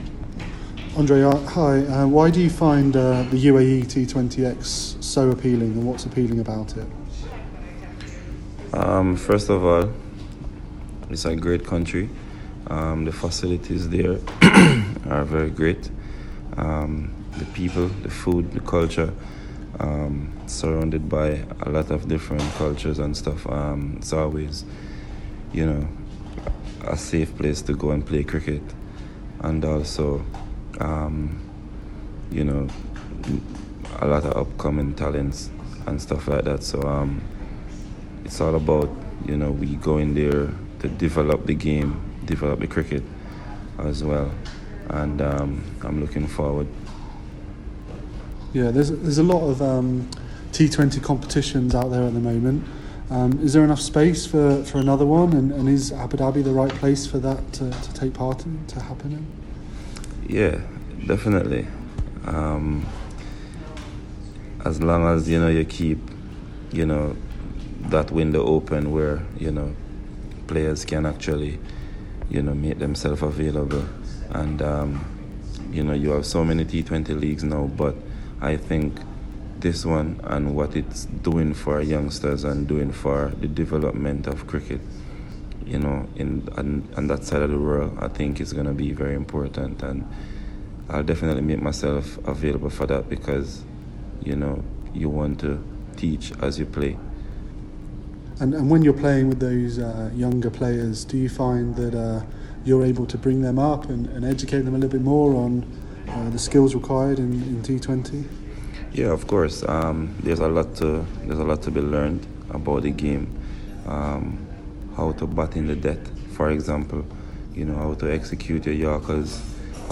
Andre Russell UAE T20 Interview